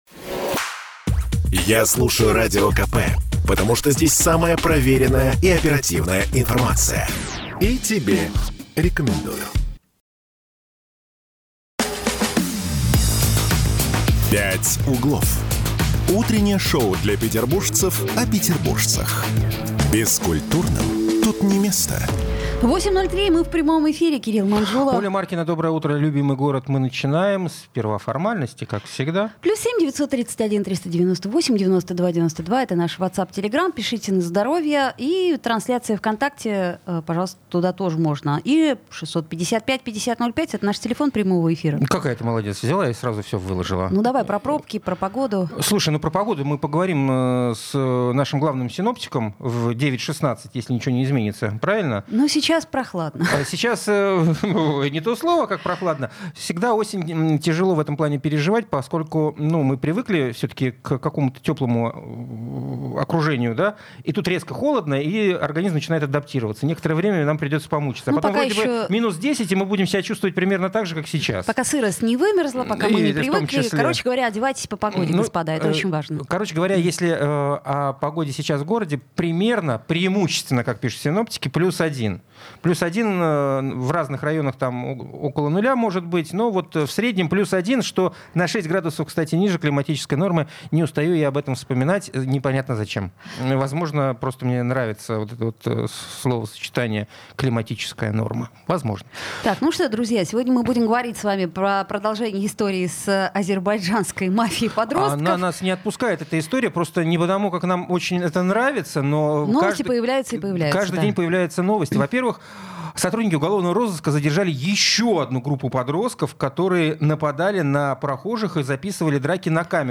Источник: Радио Комсомольская правда